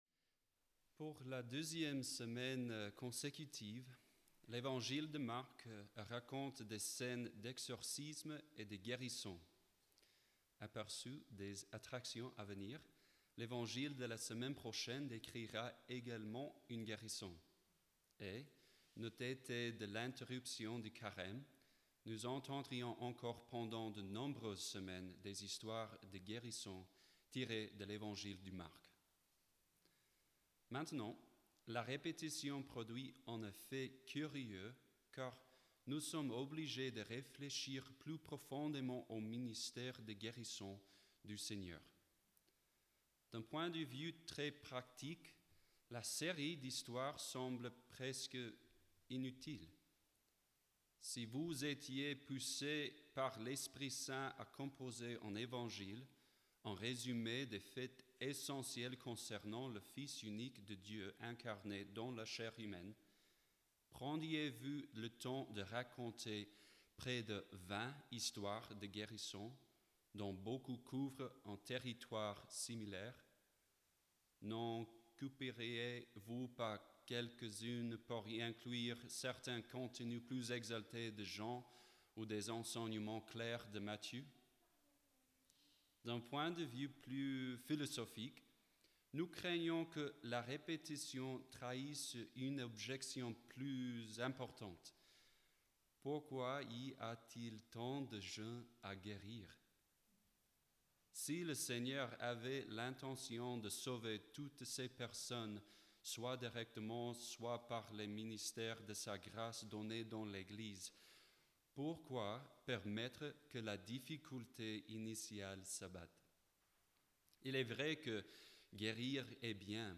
Pour ce dimanche de la 5ème semaine du temps ordinaire